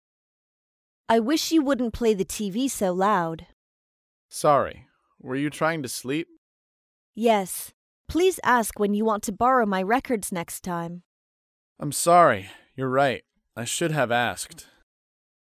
英语情景对话